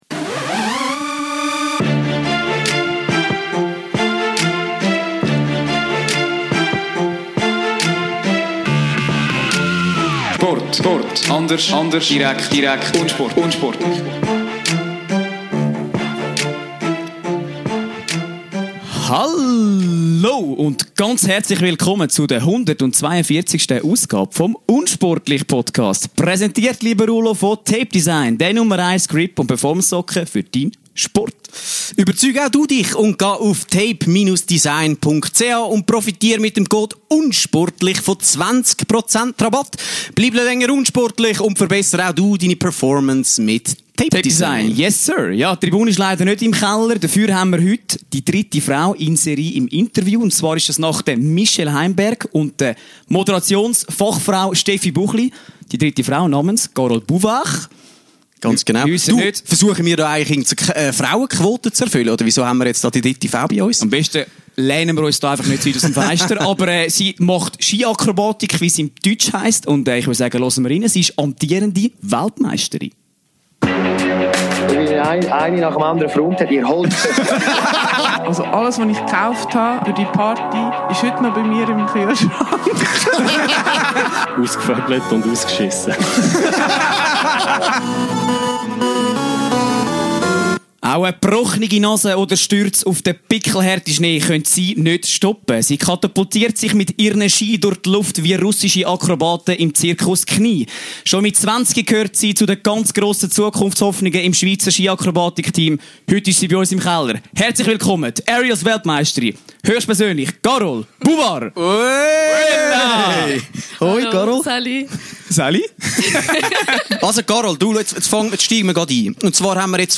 Natürlich sprechen wir mit ihr über die ausgiebigen Feierlichkeiten nach dem WM-Erfolg in Deer Valley und wie sie im Sommer jeweils mit Neopren-Anzug und Schwimmweste ausgestattet ihre Stunts trainiert und dabei Hals über Kopf im Swimming Pool landet! Auch wenn sie sich im unsportlich-Keller vor Nervosität kaum mehr ruhig halten konnte, avancieren die legendären Questions of the Internet wie immer zum absoluten Knüller!